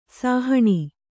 ♪ sahaṇa